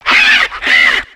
Cri de Vaututrice dans Pokémon X et Y.